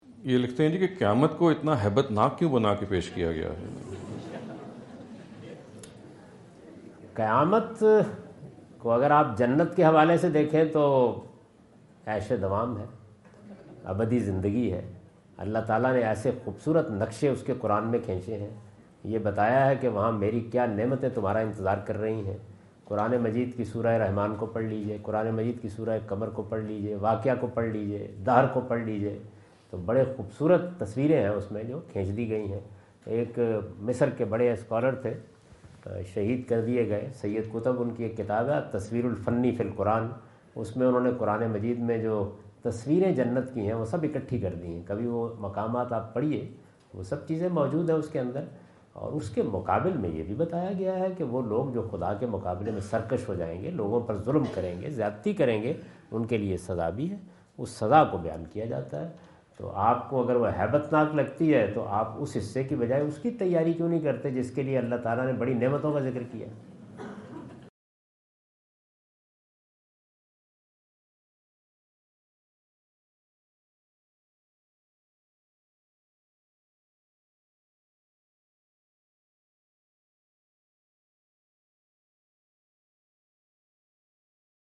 Category: English Subtitled / Questions_Answers /
Javed Ahmad Ghamidi answer the question about "Quranic Description of the Day of Judgment" asked at Corona (Los Angeles) on October 22,2017.
جاوید احمد غامدی اپنے دورہ امریکہ 2017 کے دوران کورونا (لاس اینجلس) میں "قرآن میں یومِ قیامت کی ہولناکیوں کا بیان" سے متعلق ایک سوال کا جواب دے رہے ہیں۔